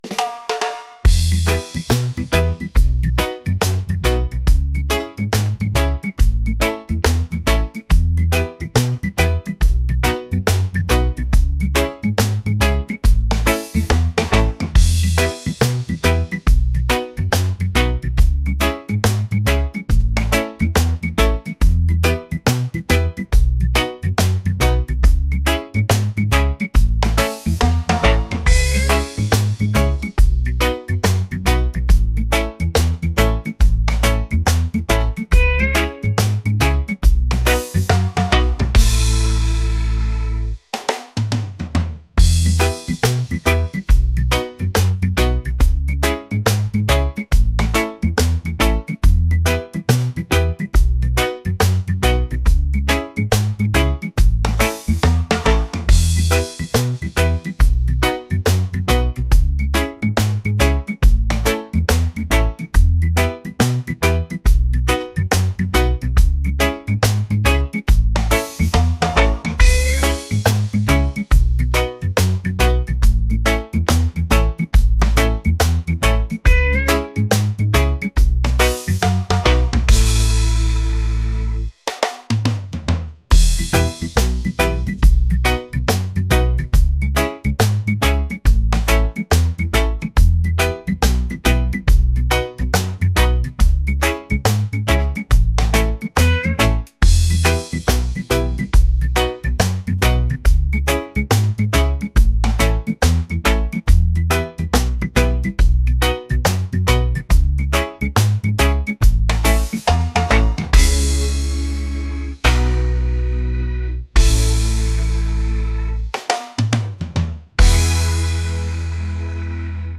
reggae | folk | pop